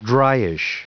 Prononciation du mot dryish en anglais (fichier audio)
Prononciation du mot : dryish